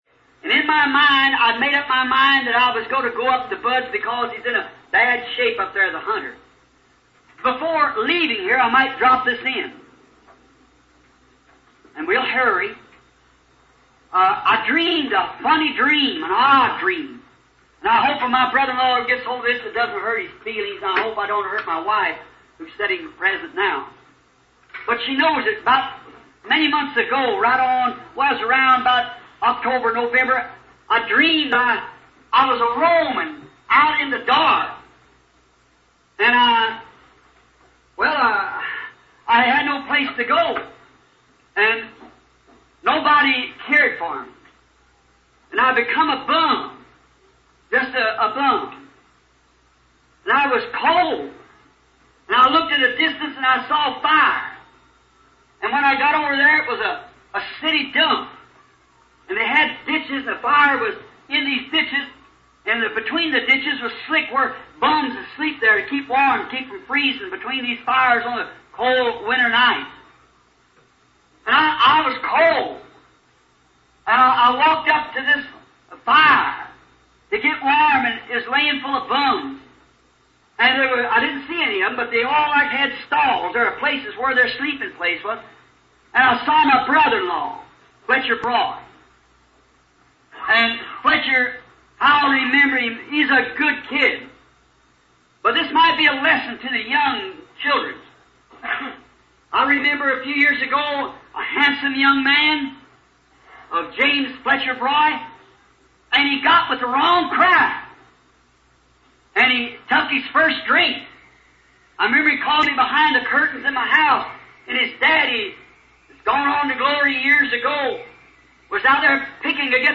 [ MP3 Audio Excerpt ] - From "Standing In The Gap," June 23, 1963 .